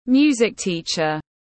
Giáo viên dạy nhạc tiếng anh gọi là music teacher, phiên âm tiếng anh đọc là /ˈmjuː.zɪk ˈtiː.tʃər/.
Music-teacher.mp3